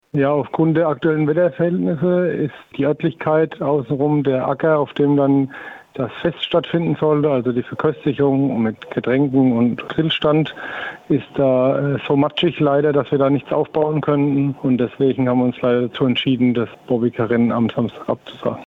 Interview: Warum wird das Bobby Car Rennen in Ostheim abgesagt?